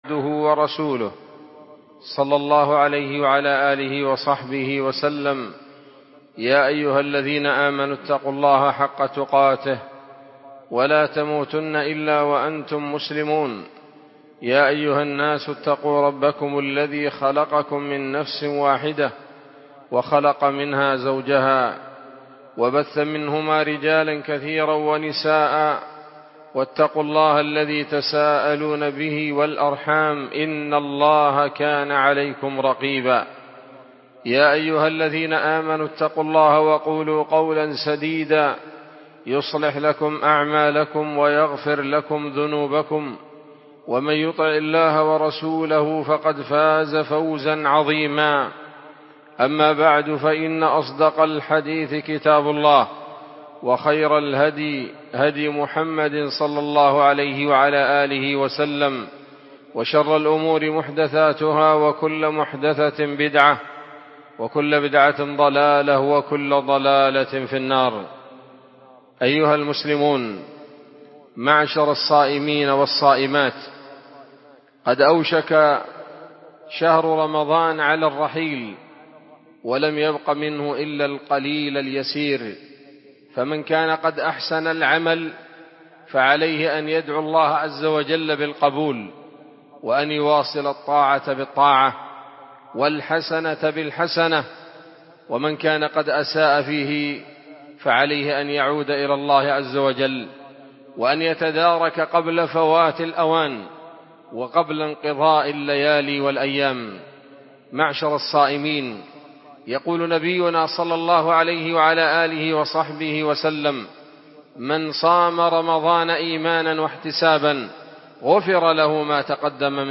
خطبة-جمعة-بعنوان-وداع-رمضان.mp3